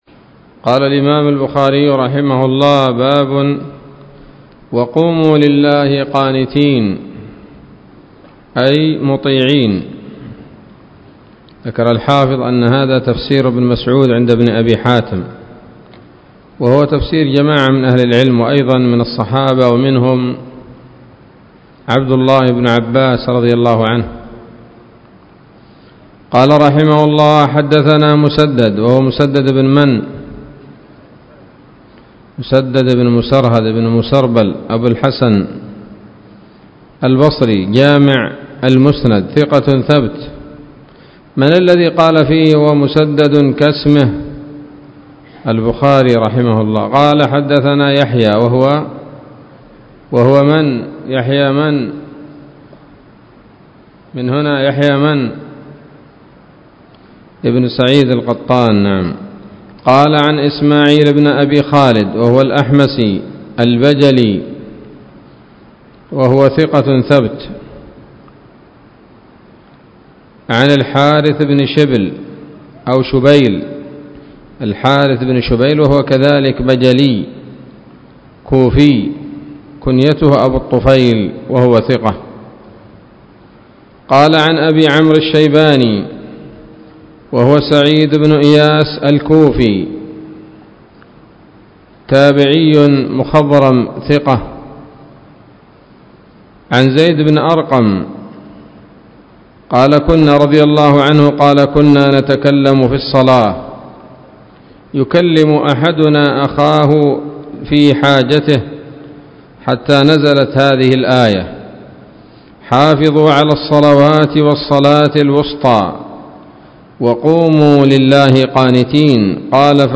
الدرس السادس والثلاثون من كتاب التفسير من صحيح الإمام البخاري